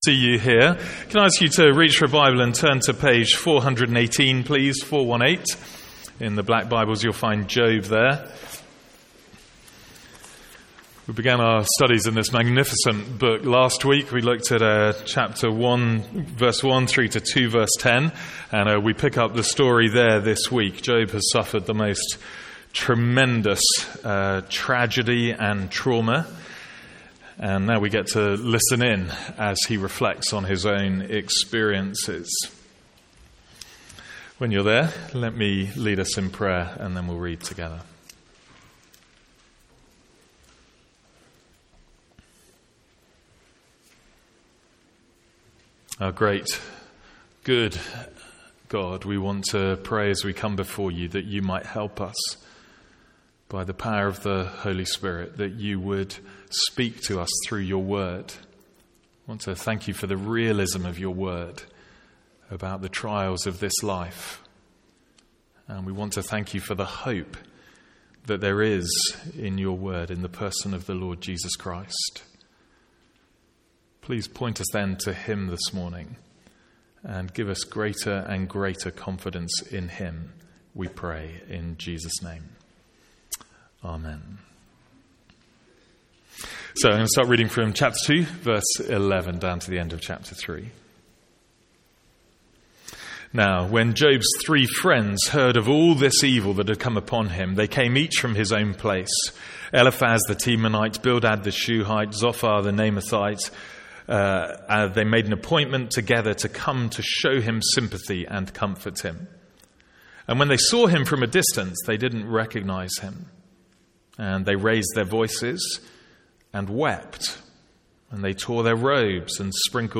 From our morning service in Job.